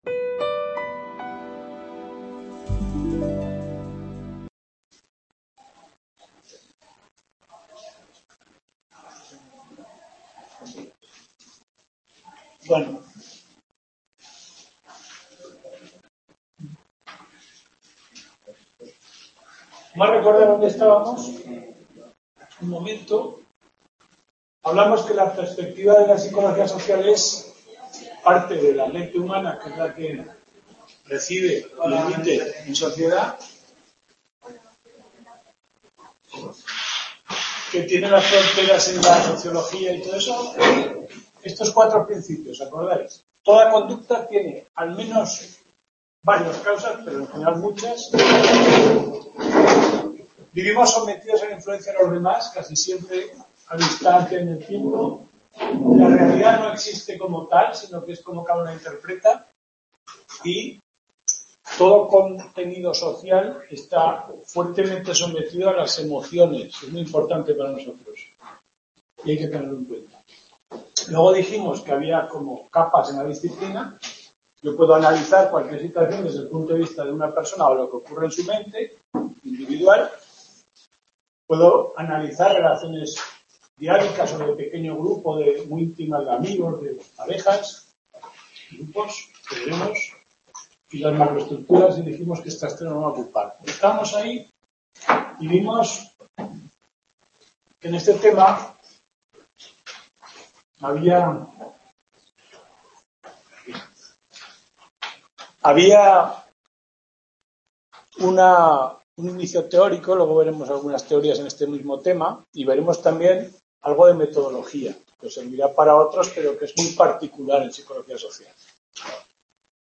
Tudela 17/10/2019 Description Sesión 2ª tutoría.